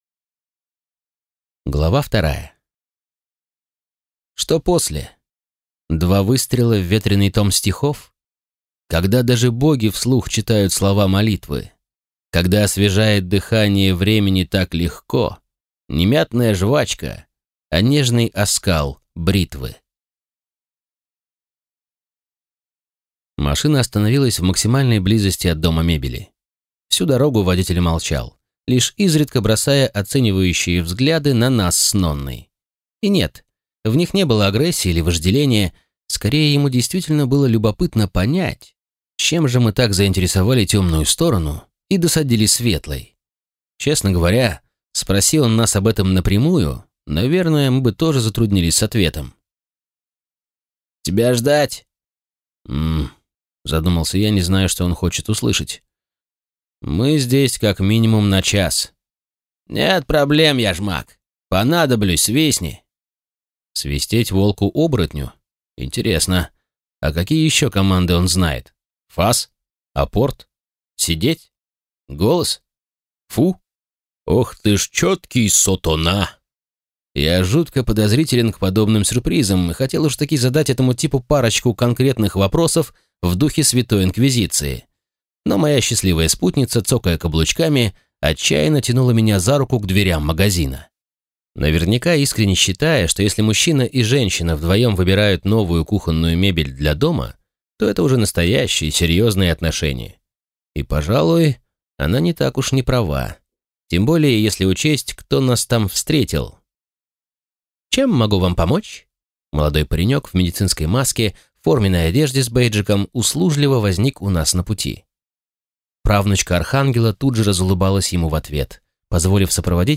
Аудиокнига Яжмаг. Клуб отверженных магов | Библиотека аудиокниг